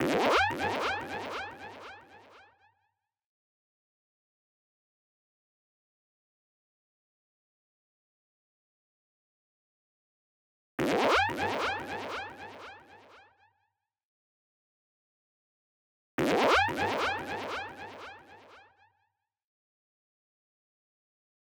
03 sfx 1.wav